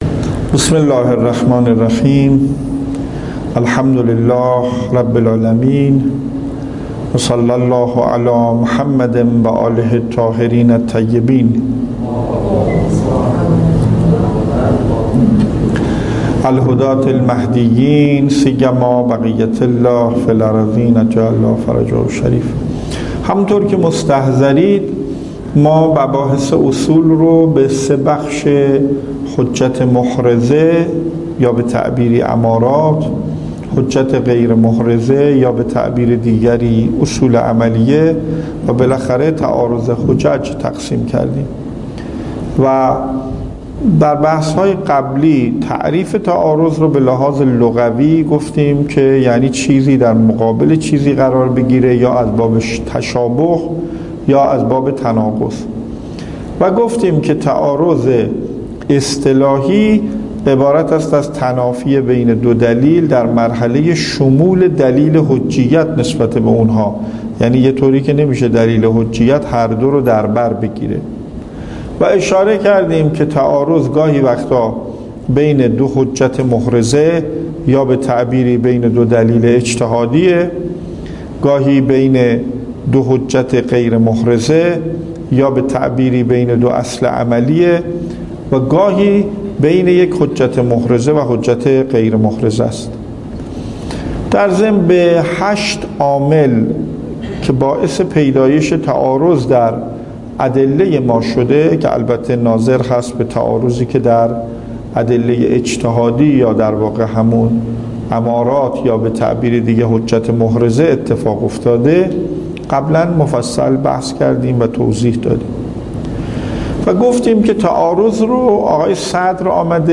درس خارج اصول